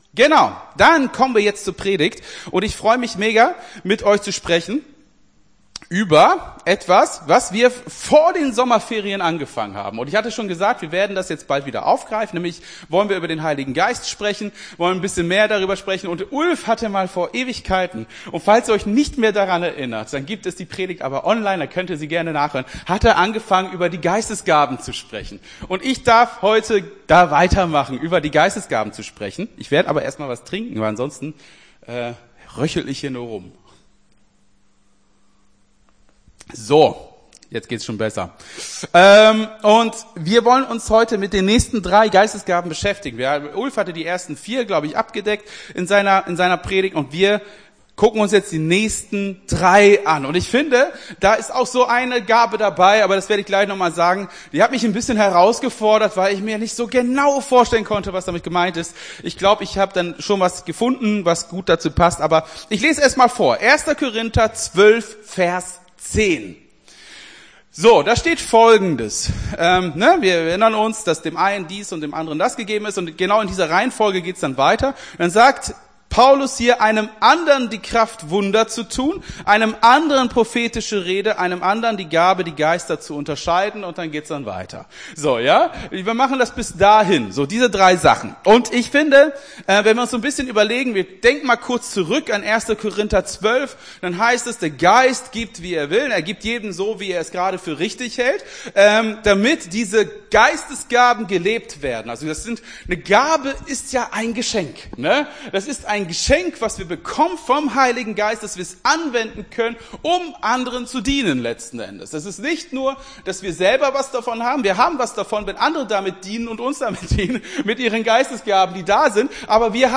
Gottesdienst 24.09.23 - FCG Hagen